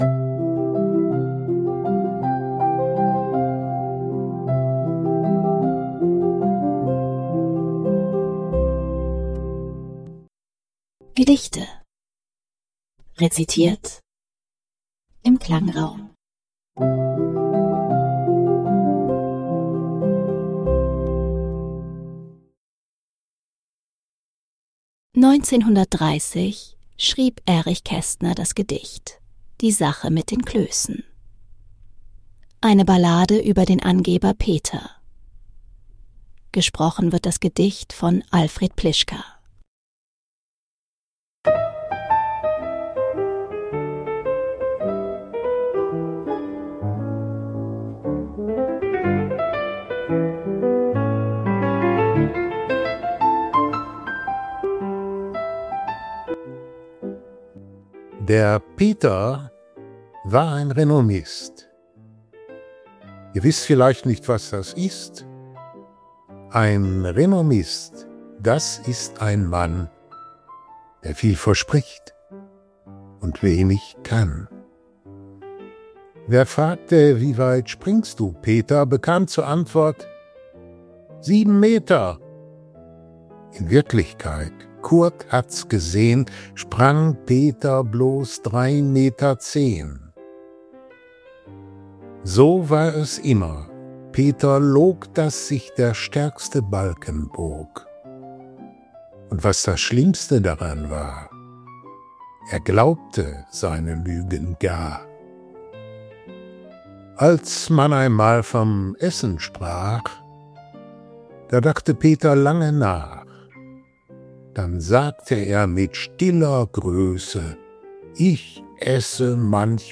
Die Hintergrundmusik wurden mit KI erzeugt. 2025